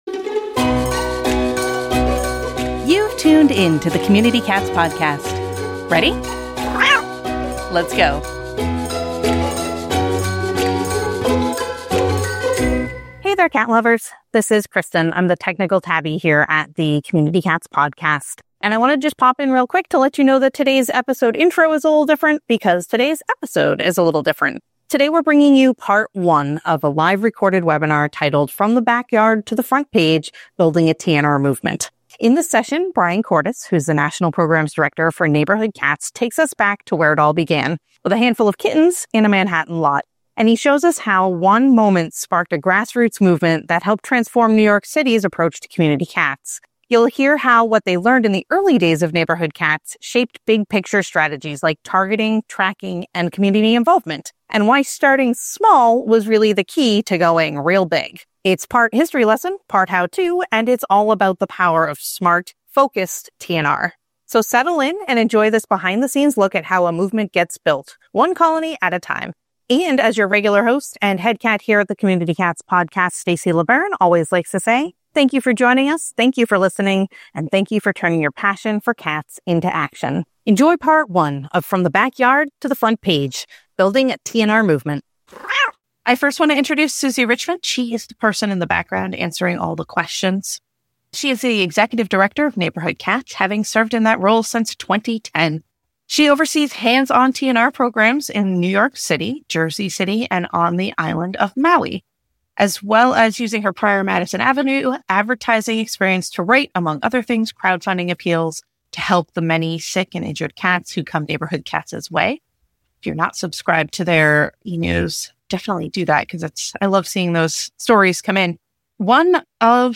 Originally presented live, this session offers listeners a chance to hear how a single encounter with kittens in a Manhattan lot sparked a grassroots movement that helped reshape New York City’s approach to community cats.